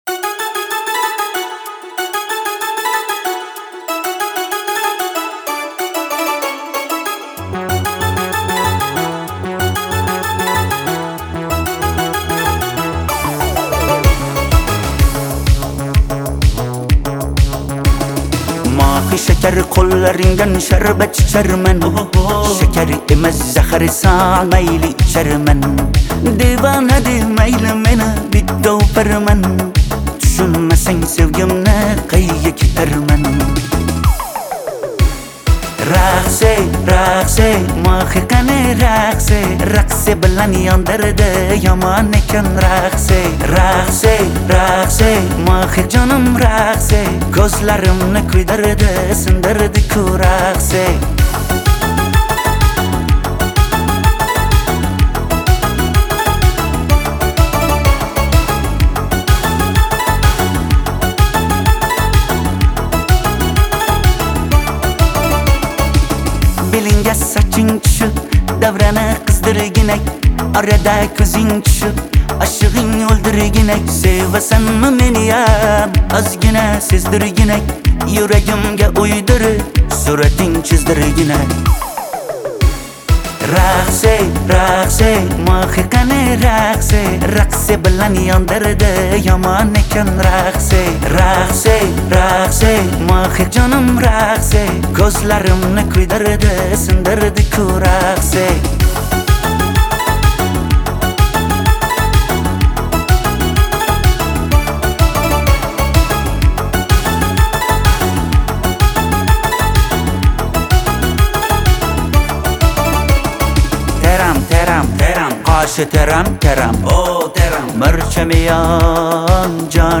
Музыка / 2026-год / Узбекские / Поп